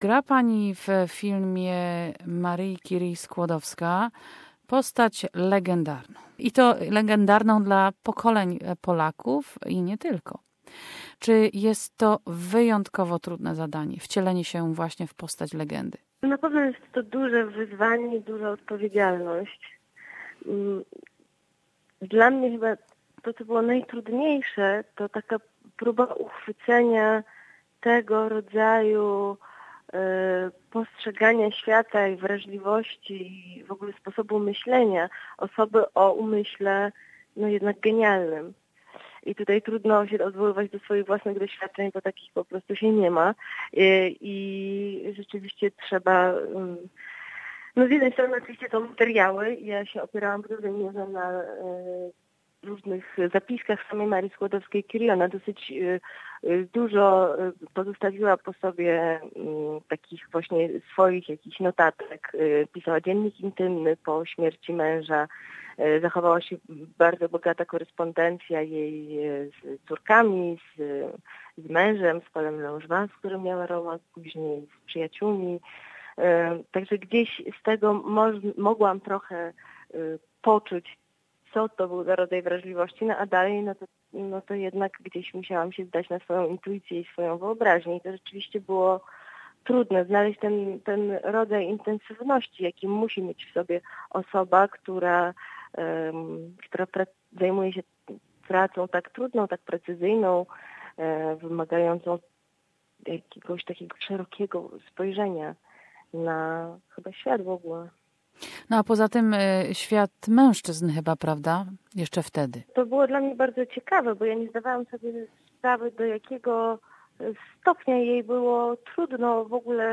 ‘Marie Curie”: an interview with actor Karolina Gruszka
Polish Film Festival in Australia presents an exciting piece of Polish cinema "Maria Sklodowska-Curie". Listen to the interview with actor Karolina Gruszka who plays in the fascinating biopic of Marie Curie.